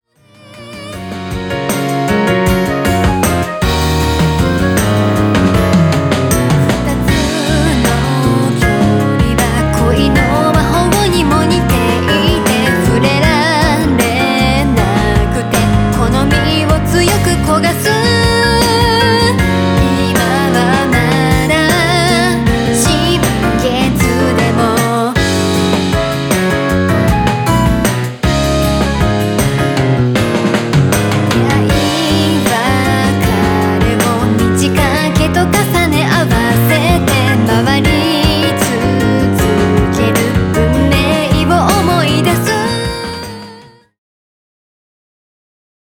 J-POP バラード